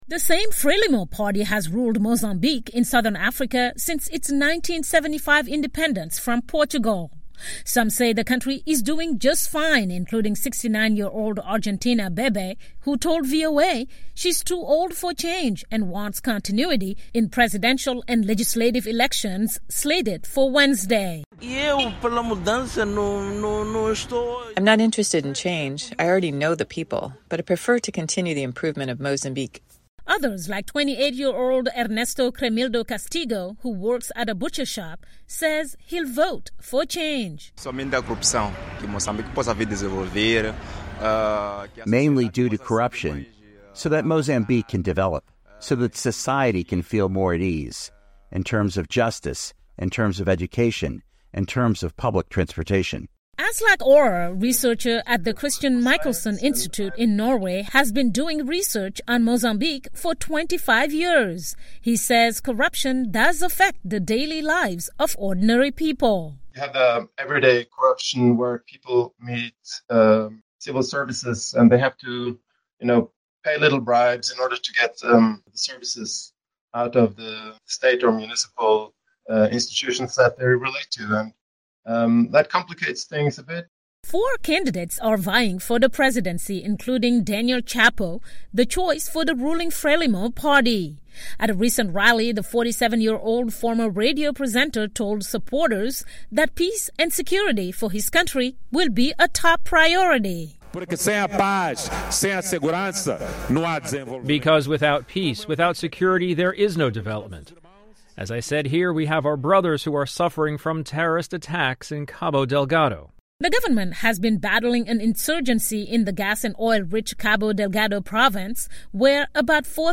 reports from Maputo